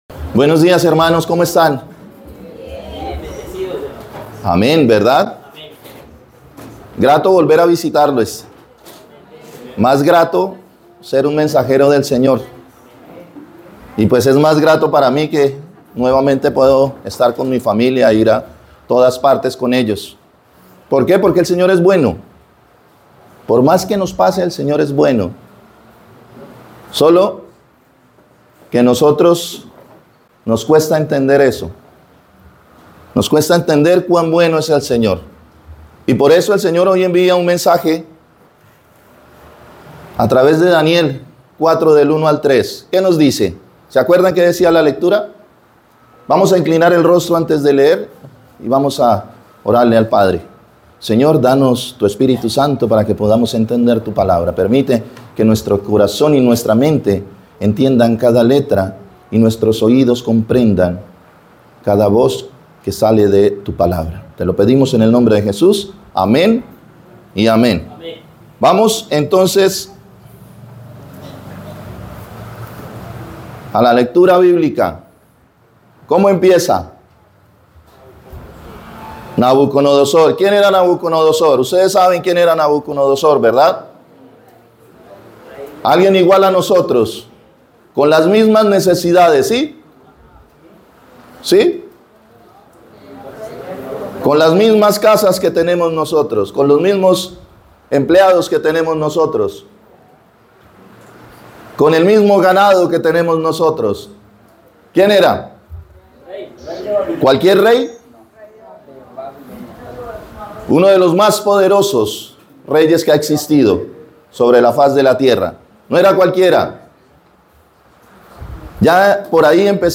Sitio web oficial de la Iglesia Adventista de Tocaima Roca Eterna, muchas gracias por visitar nuestra página.